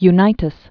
U·ni·tas
(y-nītəs), John Constantine Known as "Johnny." 1933-2002.